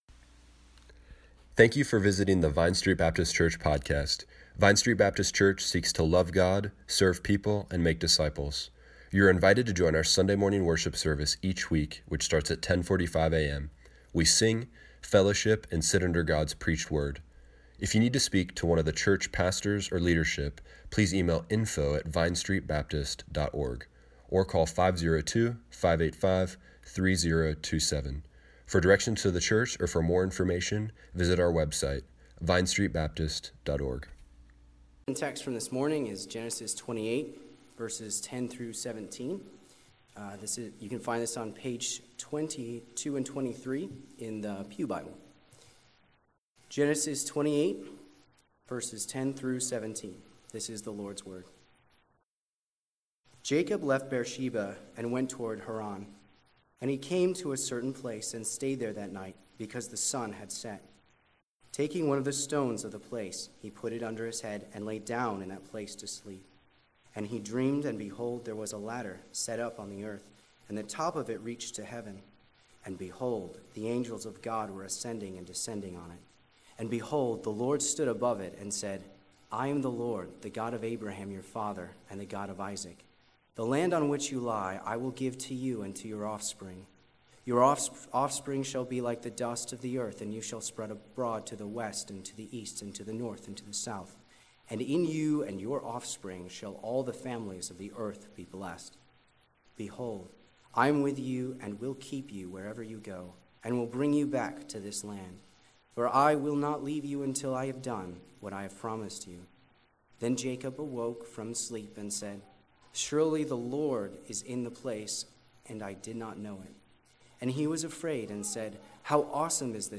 After the sermon everyone stood and sang Great is Thy Faithfulness during a time of reflection.